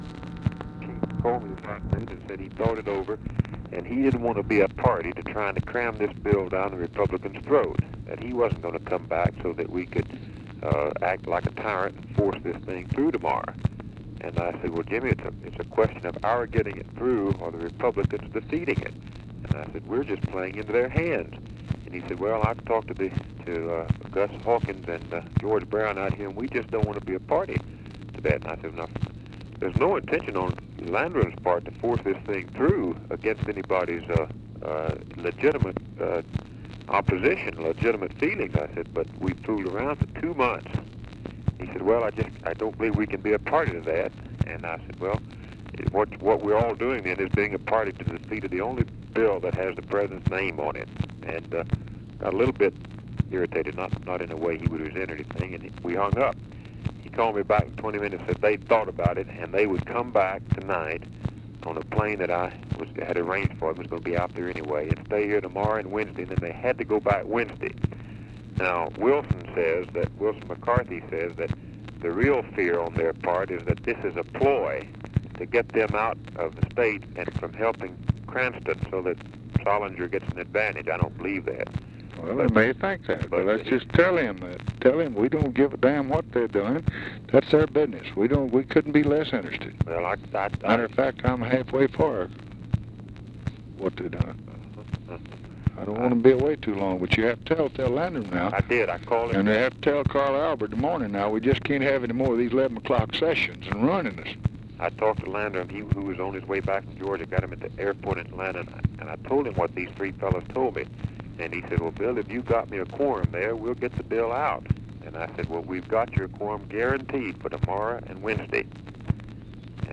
Conversation with BILL MOYERS, May 26, 1964
Secret White House Tapes